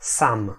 Ääntäminen
IPA: [sœl]